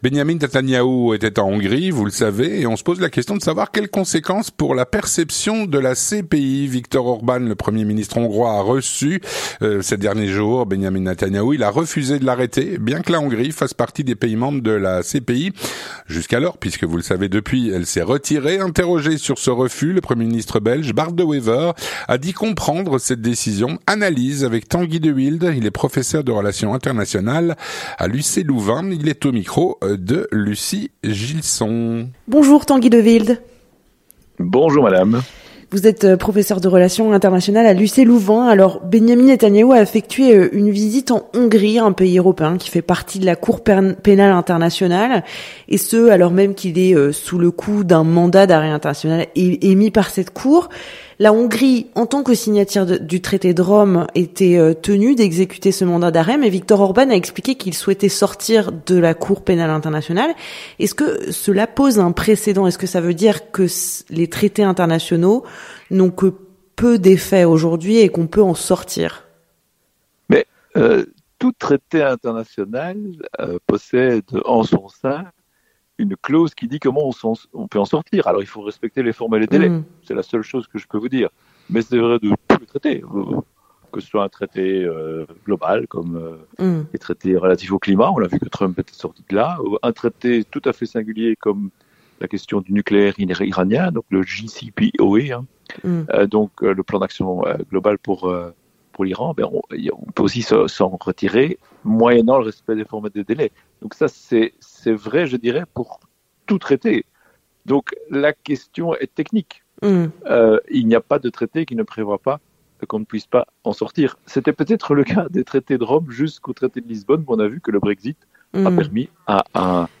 L'entretien du 18H - Benjamin Netanyahou en Hongrie : quelles conséquences pour la perception de la CPI ?